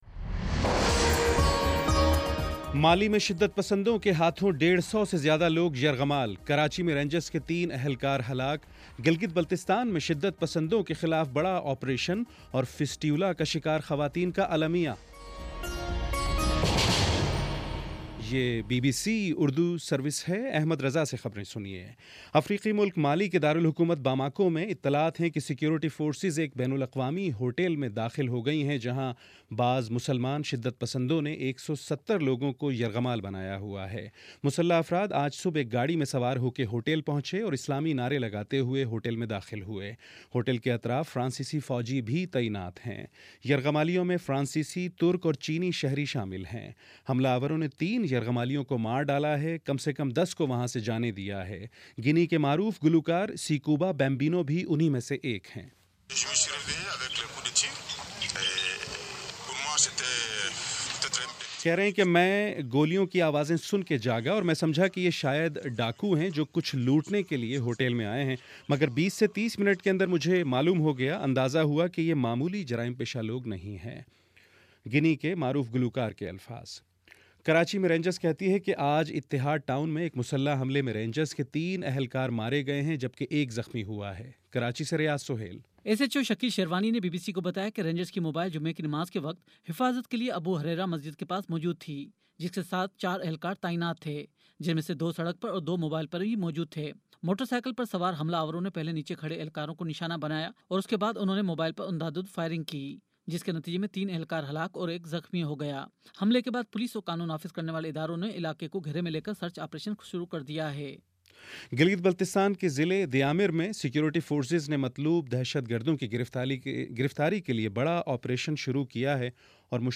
نومبر 20 : شام پانچ بجے کا نیوز بُلیٹن